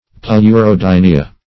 Pleurodynia \Pleu`ro*dyn"i*a\, n. [NL., fr. Gr.